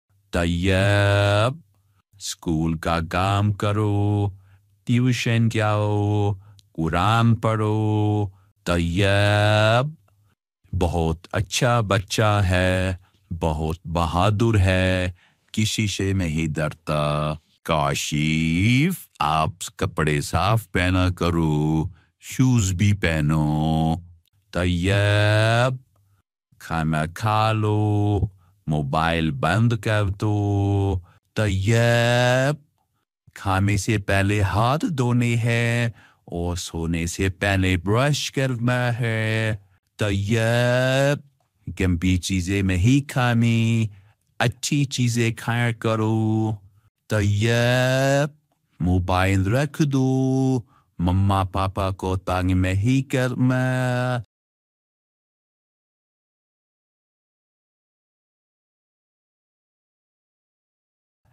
Goat Calling
You Just Search Sound Effects And Download. tiktok comedy sound effects mp3 download Download Sound Effect Home